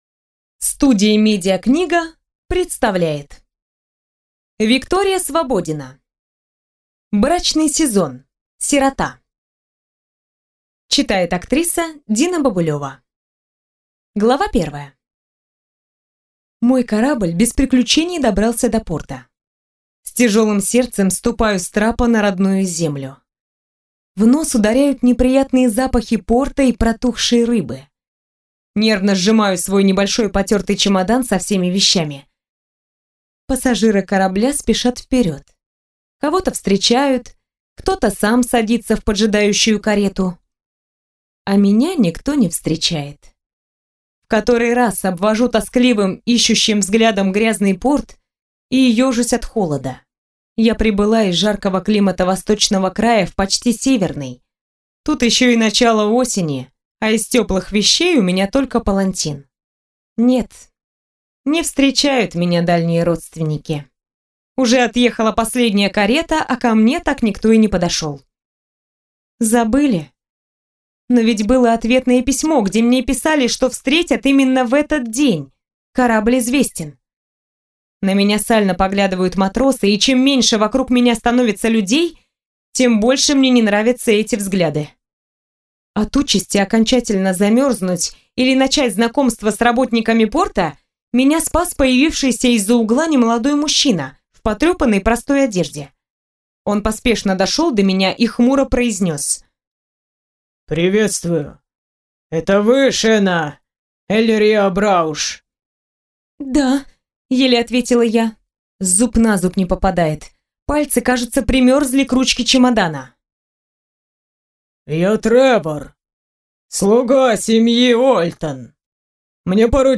Аудиокнига Брачный сезон. Сирота - купить, скачать и слушать онлайн | КнигоПоиск